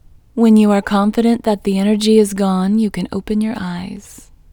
IN Technique First Way – Female English 31